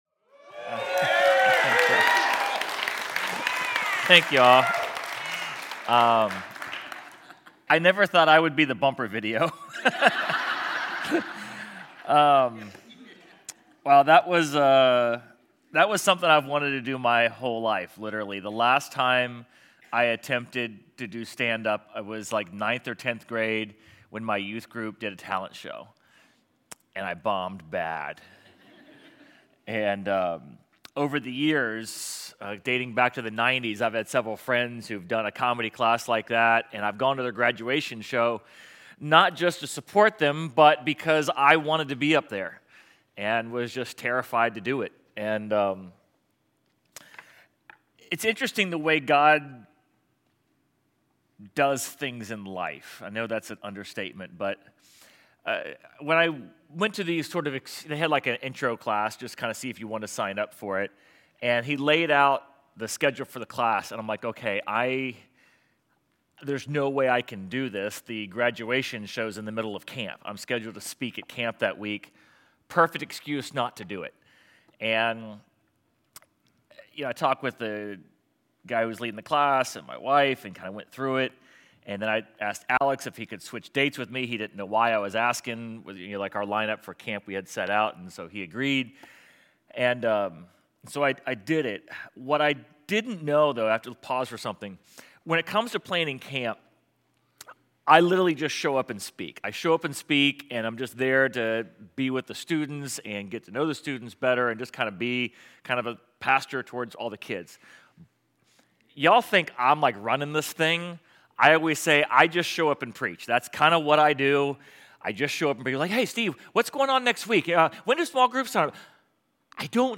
Sermon_8.10.25.mp3